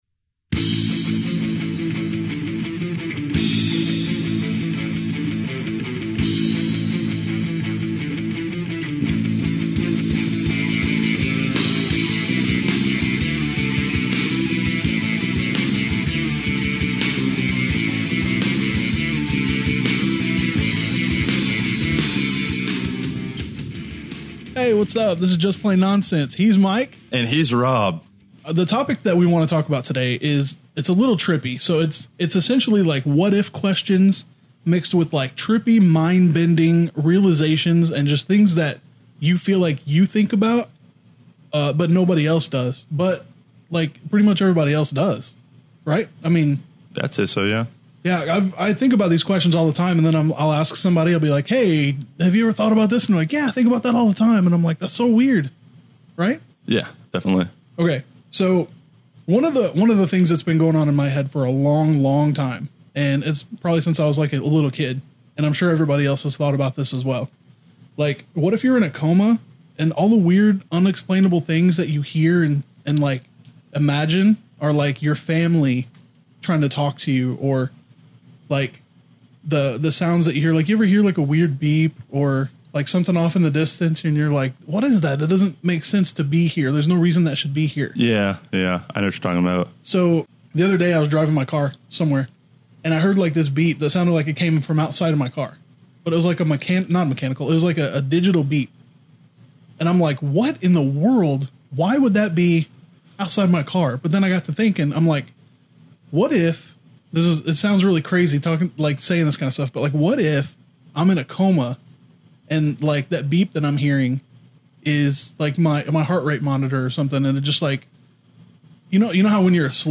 Oh, and there's some terrible singing as well.